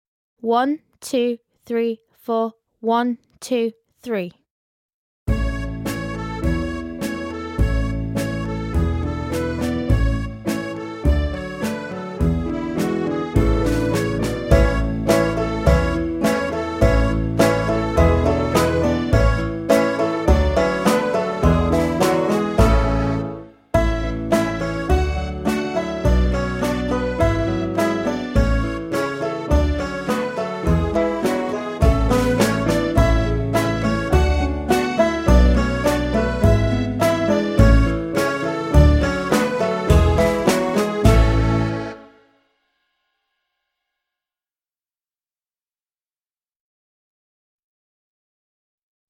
VS Gow's Reel (backing track)